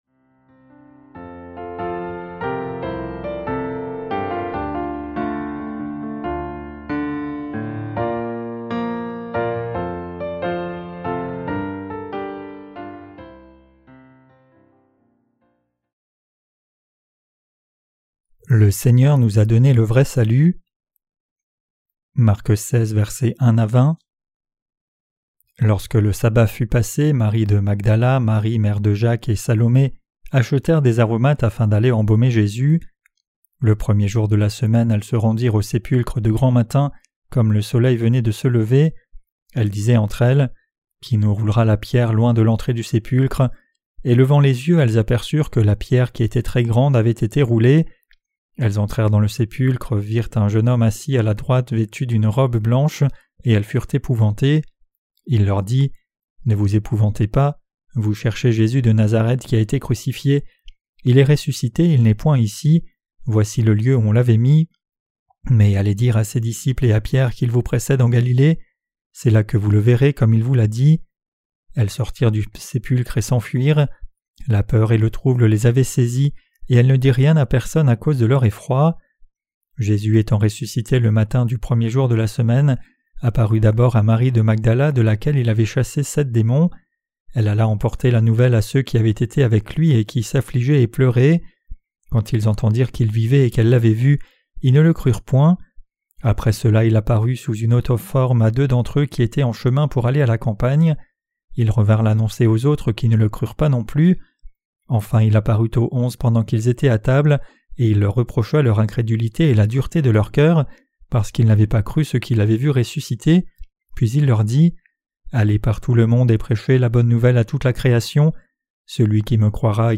Sermons sur l’Evangile de Marc (Ⅲ) - LA BÉNÉDICTION DE LA FOI REÇUE AVEC LE CŒUR 12.